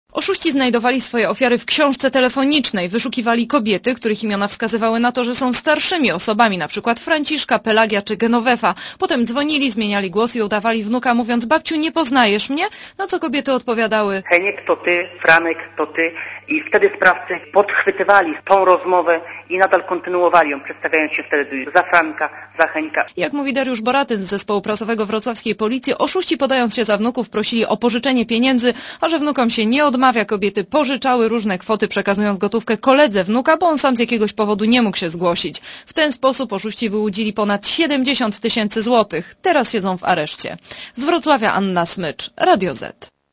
Posłuchaj relacji reporterki Radia Zet (168 KB)